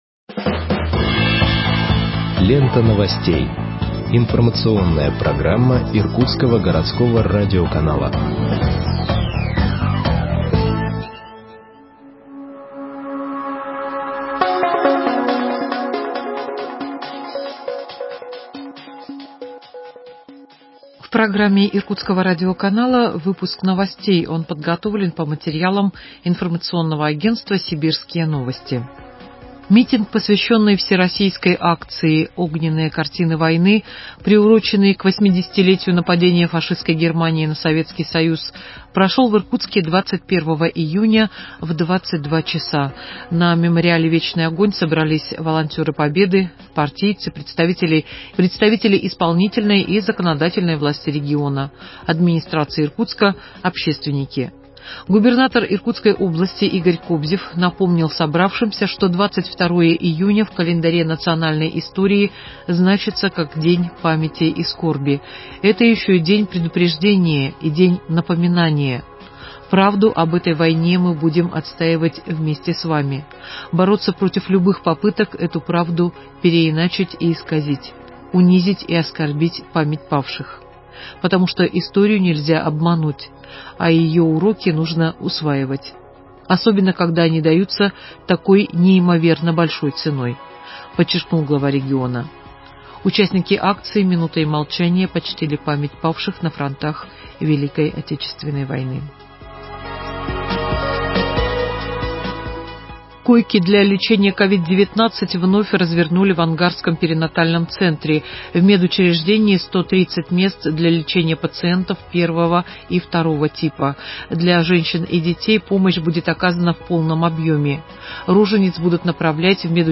Выпуск новостей в подкастах газеты Иркутск от 22.06.2021 № 2